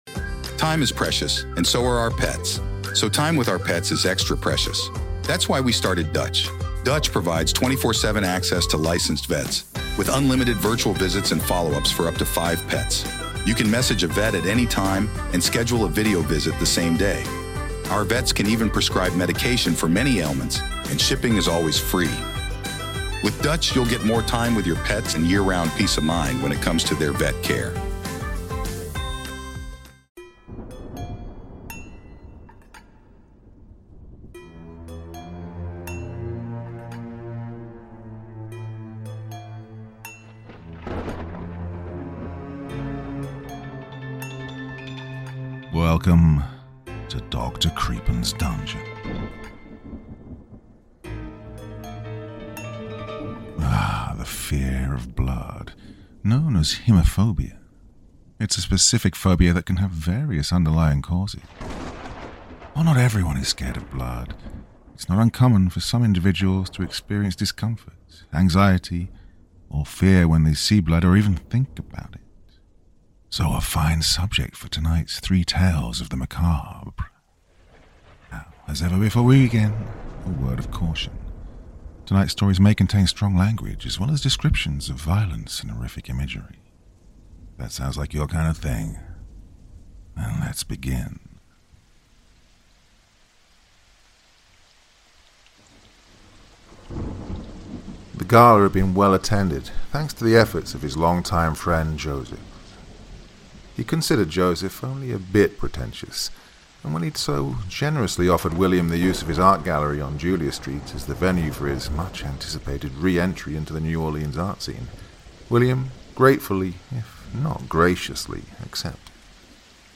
narrated here for you all under the conditions of the CC-BY-SA license